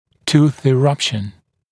[tuːθ ɪ’rʌpʃn][ту:с и’рапшн]прорезывание зуба/зубов